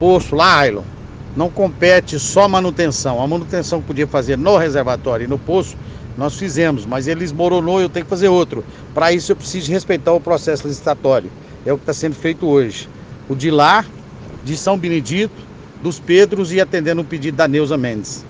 A reportagem repassou as reclamações para o setor do DAEPA e ao secretario do prefeito e a respostas foram….Voz do superintendente Ronaldo Correia de Lima.👂👂👇👇👇.